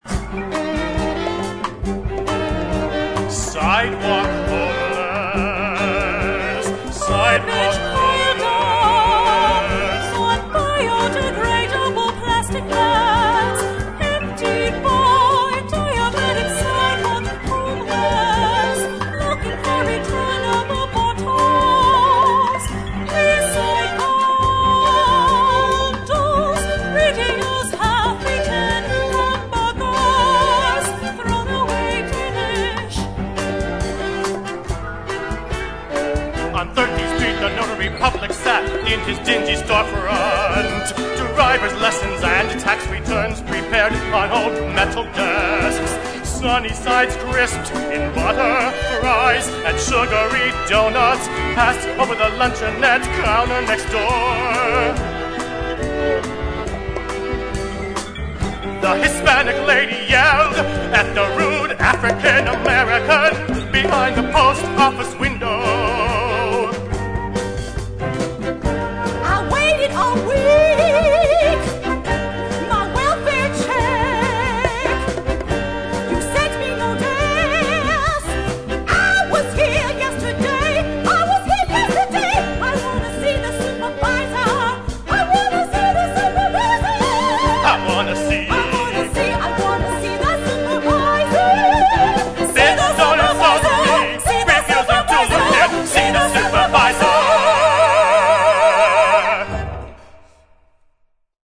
An unusual melodic setting of Ginsberg's poetry